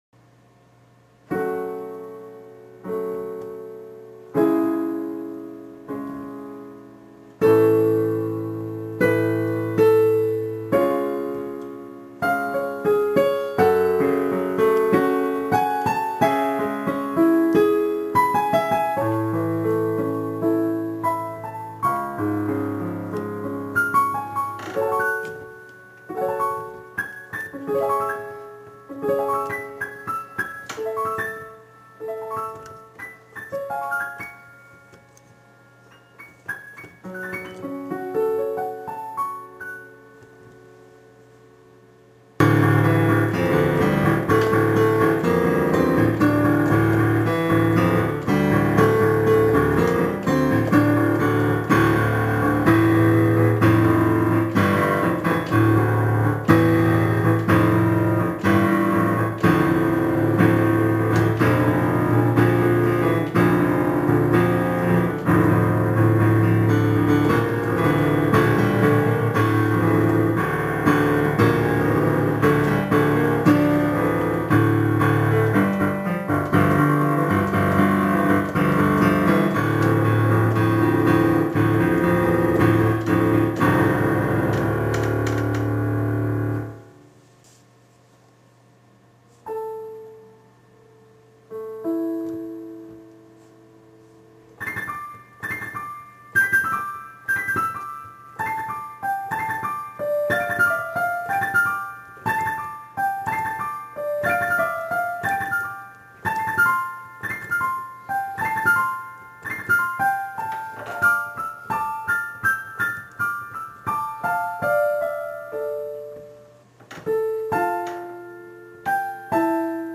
Enregistrement piano 1.mp3
enregistrement-piano-1.mp3